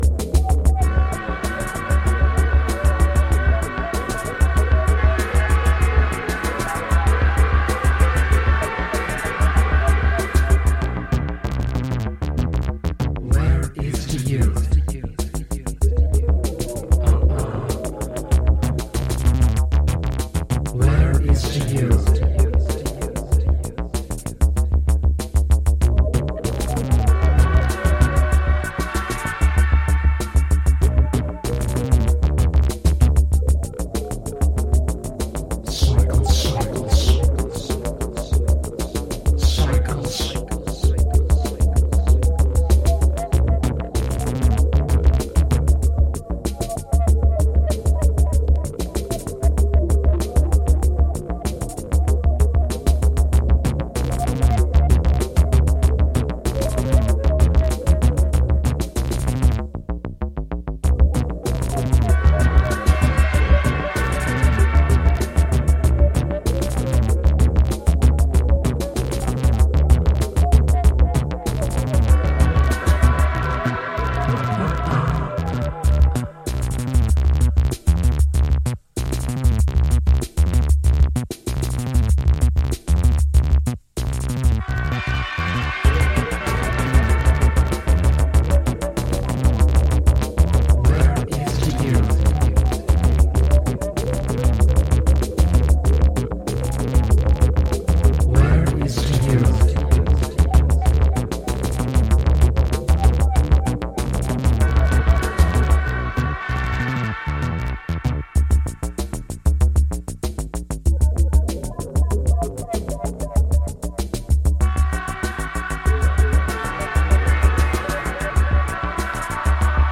some dark sort-of New Beat tracks
some deep techno which teases with acid qualities.
Electro Acid Wave